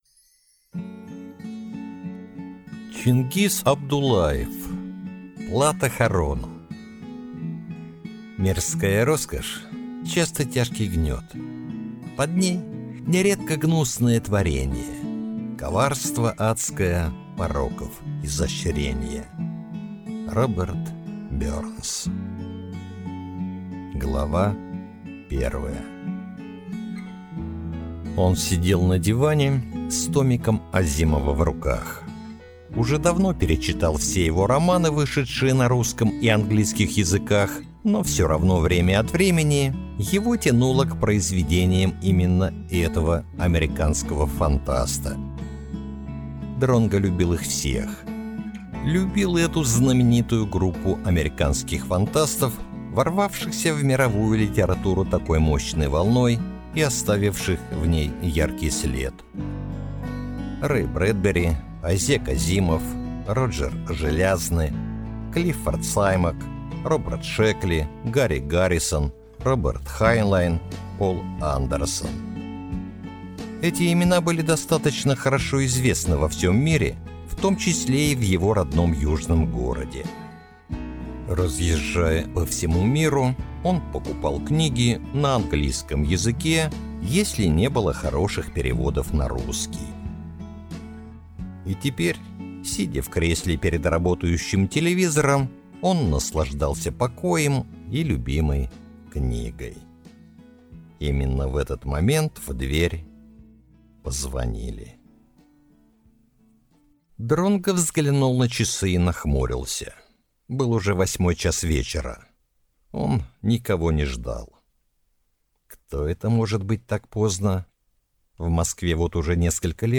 Аудиокнига Плата Харону | Библиотека аудиокниг